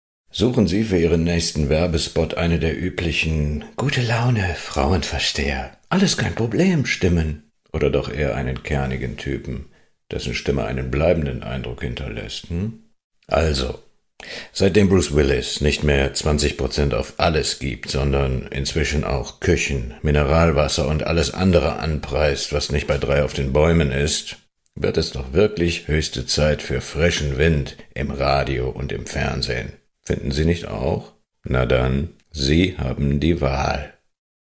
德语样音试听下载
德语配音员（男1） 德语配音员（男2）